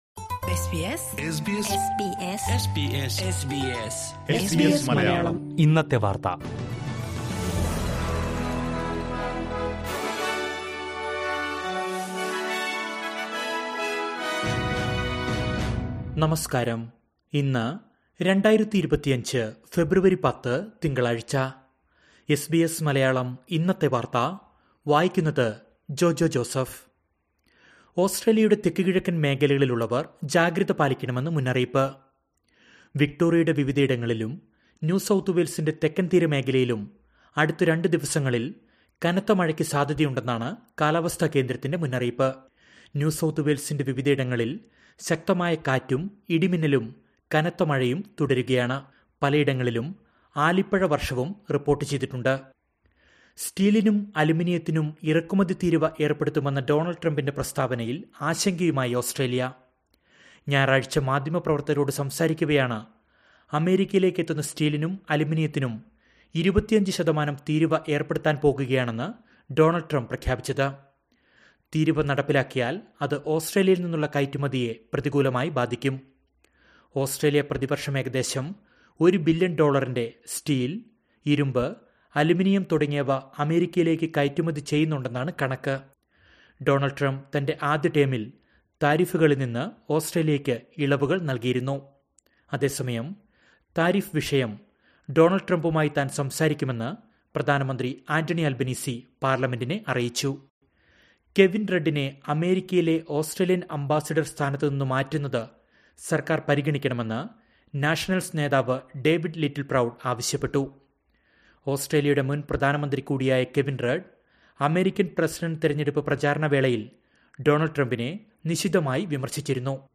2025 ഫെബ്രുവരി 10ലെ ഓസ്‌ട്രേലിയയിലെ ഏറ്റവും പ്രധാന വാര്‍ത്തകള്‍ കേള്‍ക്കാം...